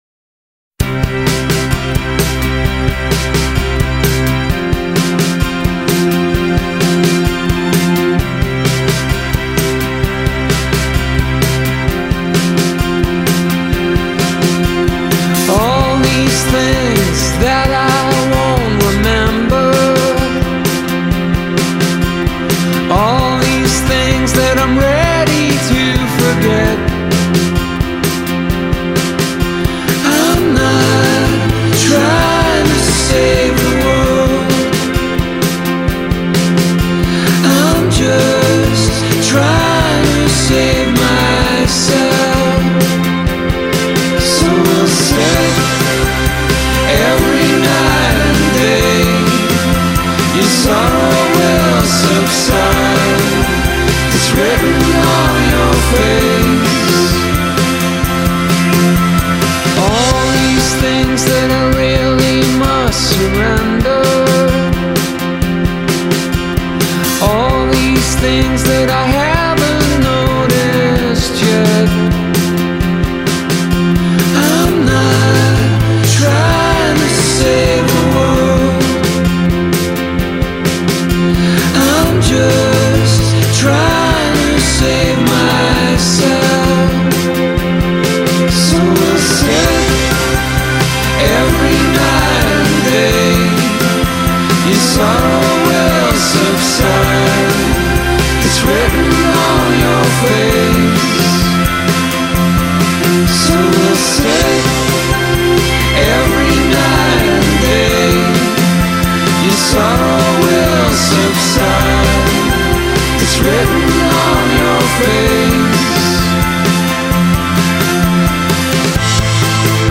a smoother type of pop
makes pop full of longing